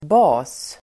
Uttal: [ba:s]